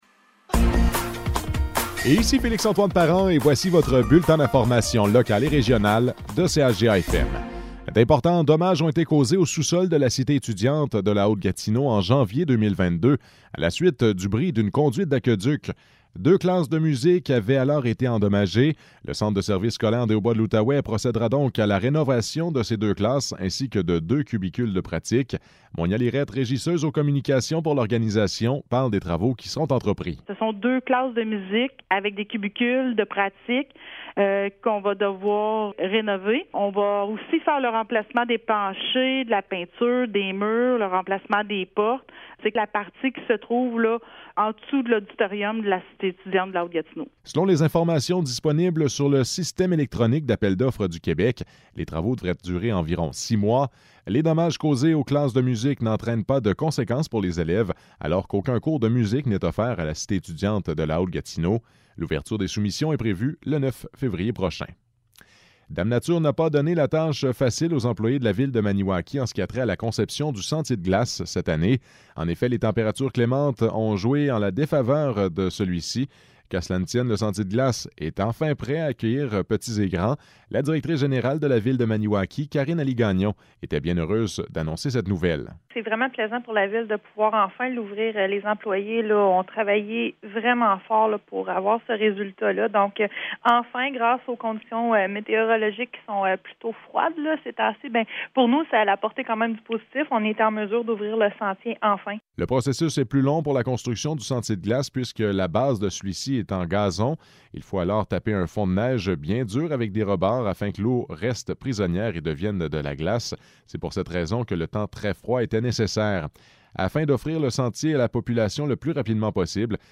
Nouvelles locales - 3 février 2023 - 12 h